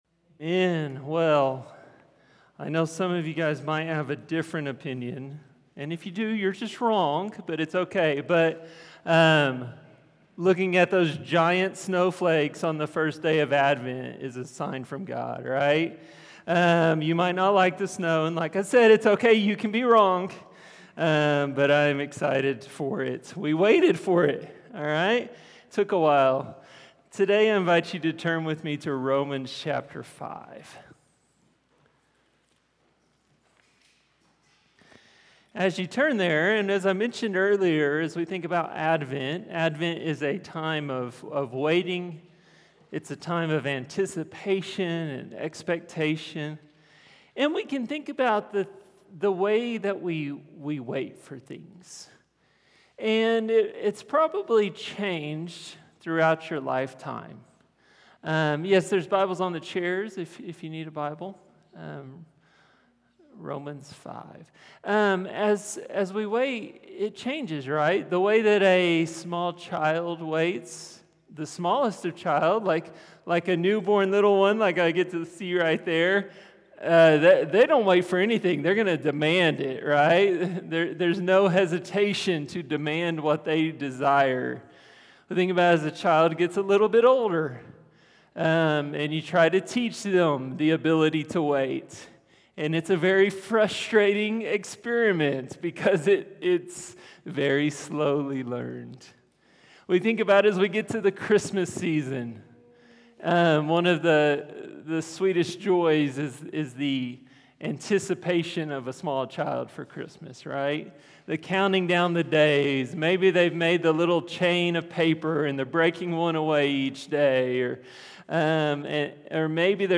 Sermons | HopeValley Church // West Jordan, UT